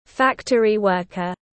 Công nhân nhà máy tiếng anh gọi là factory worker, phiên âm tiếng anh đọc là /ˈfæktəri ˈwɝːkər/.
Factory worker /ˈfæktəri ˈwɝːkər/
Factory-worker.mp3